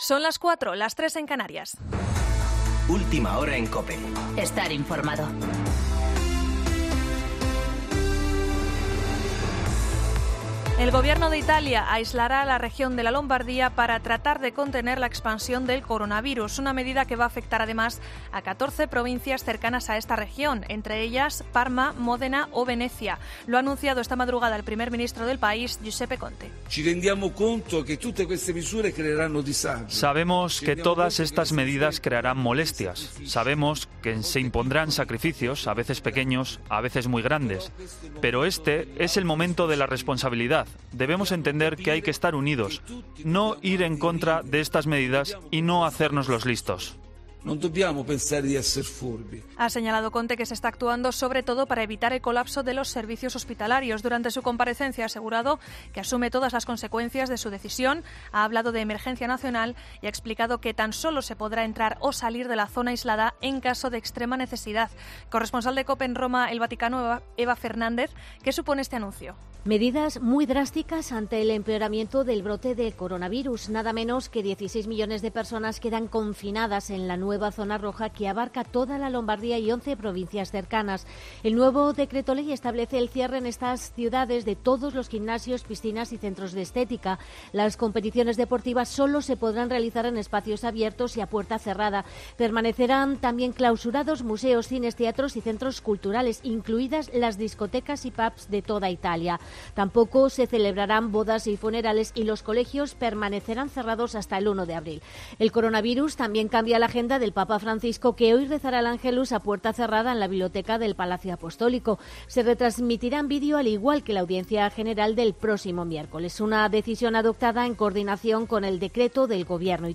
Boletín de noticias COPE del 8 de marzo de 2020 a las 4.00 horas